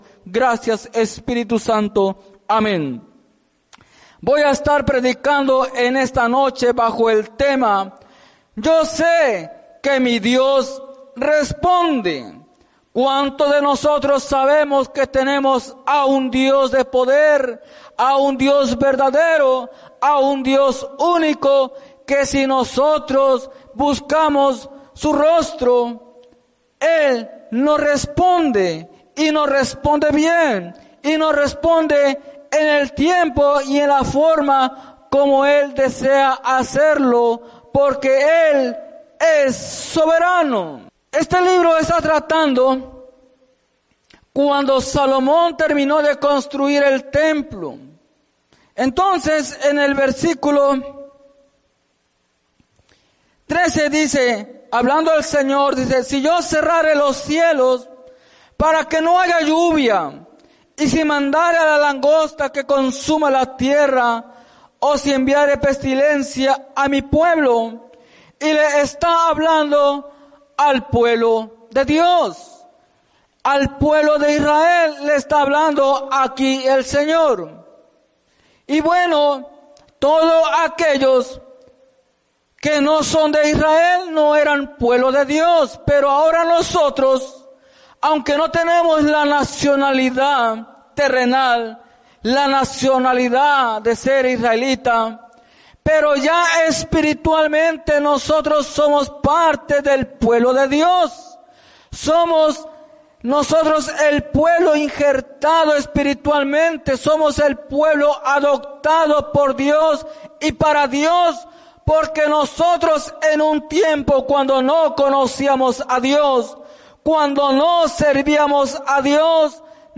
Mensaje
en la Iglesia Misión Evangélica